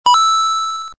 touchCoin.mp3